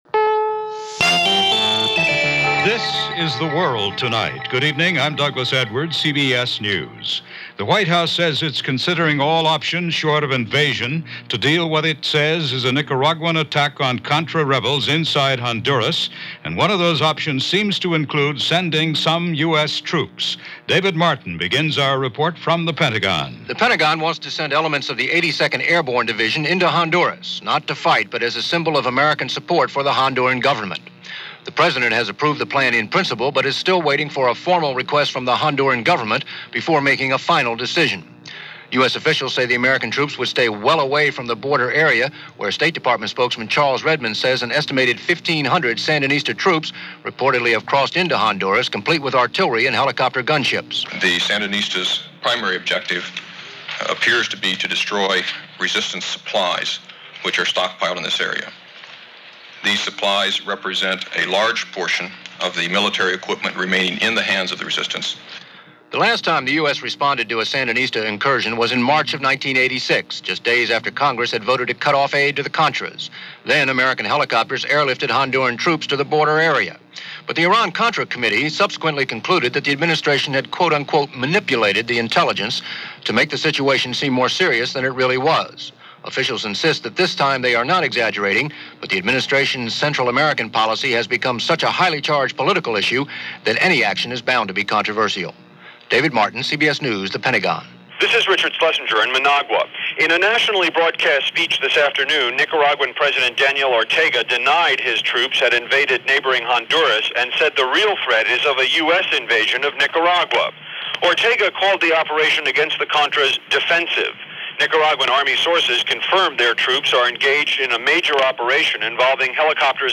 And that’s a small slice of what went on, this March 16, 1988 as reported by The World Tonight.